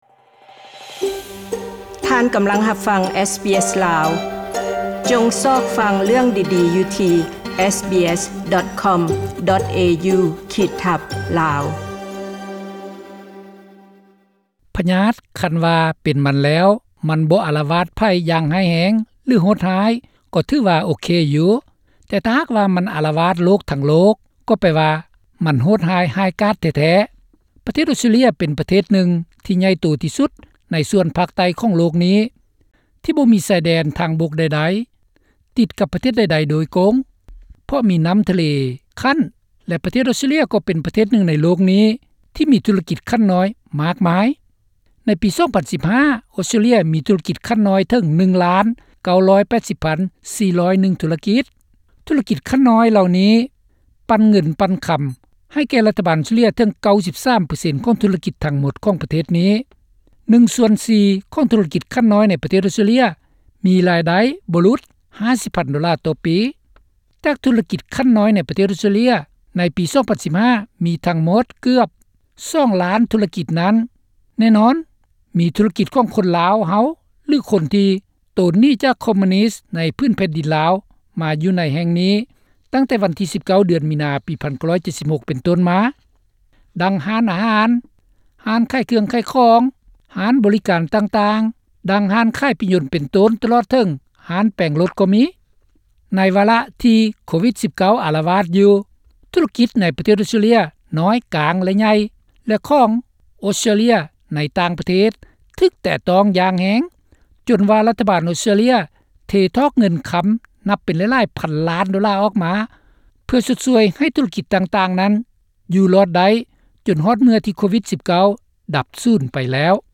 ທຸຣະກິດລາວໃນອອສເຕຼເລັຍໃນຣະຍະວິກິດ ໂກວິດ-19 (ສຳພາດ 04-4-20)